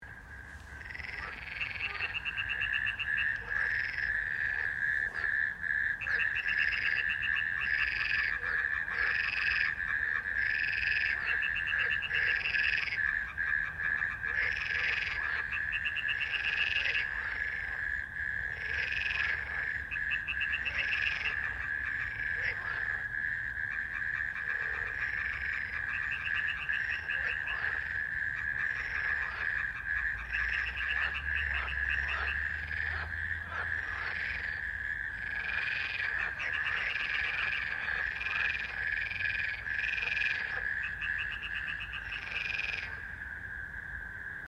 Hören Sie vorab eine Tonprobe der letztjährigen, eindrucksvollen Sinfonie der Frösche, verstärkt mit Kröten…
Der Konzertsaal in Eschenz TG bietet eine einmalige Akkustik.
Inszeniert von Frosch, Kröte und Co. Unter der Leitung vom stimmgewaltigen Laubfrosch, begleitet von Wasserfrosch und Kreuzkröte.
laubfrosch-wasserfrosch-kreuzkroete.mp3